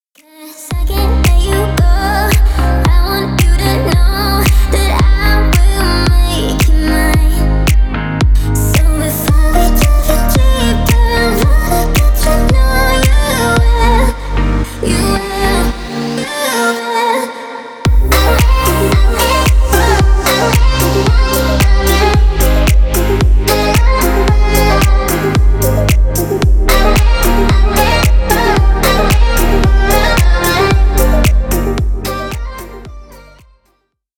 Поп Музыка # Танцевальные
клубные